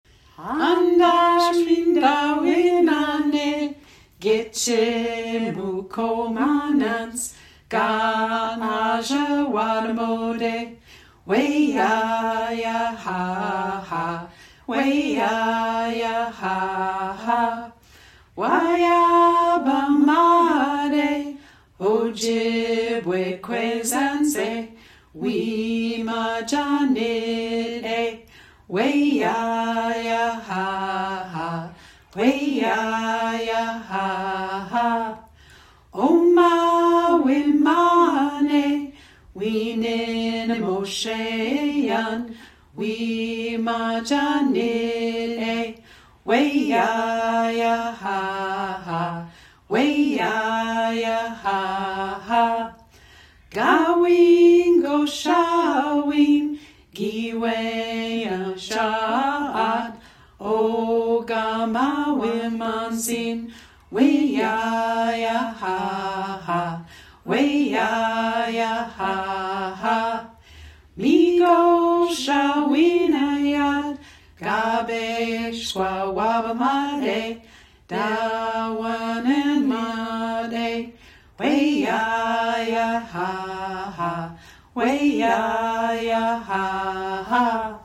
Traditional Songs
version of the song together in 2026.
This one utilizes as much of the original melodic material as possible adding in more traditional rhythmic patterns and logical language alignment. The spirit of the original transcription has been maintained while creating a version that more closely aligns with the sound of Ojibwe traditional songs.